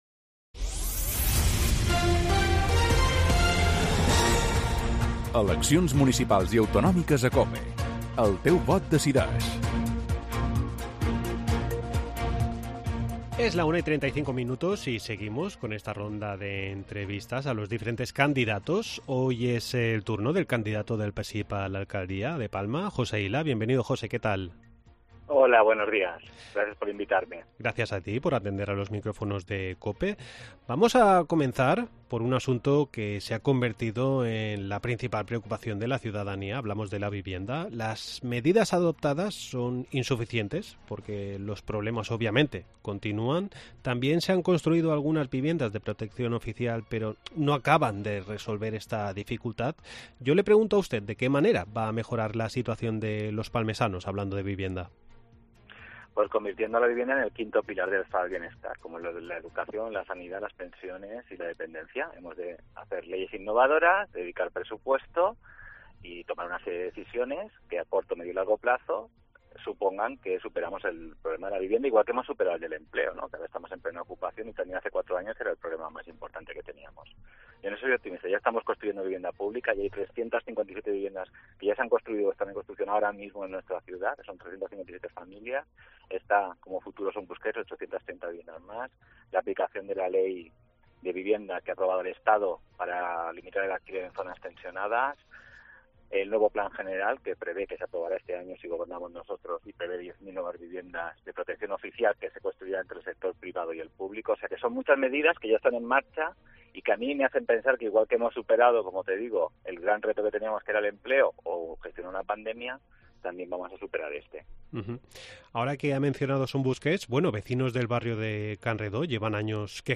AUDIO: Continuamos con las entrevistas a los candidatos de las próximas elecciones autonómicas y municipales.